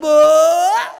SCREAM 2.wav